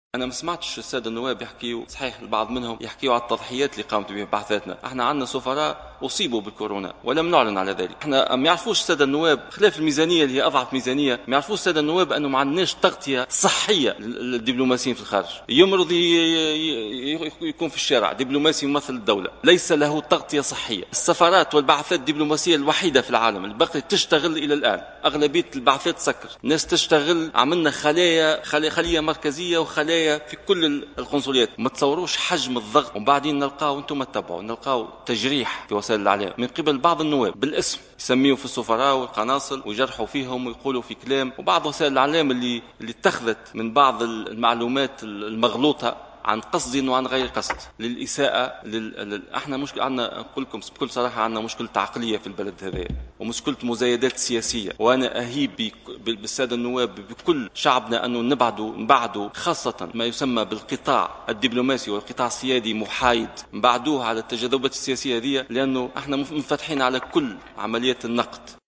وجاء ذلك لدى إجابته عن تساؤلات النواب في جلسة حوار مخصصة مع وزيري و الخارجية والنقل حول وضعية التونسيين بالخارج.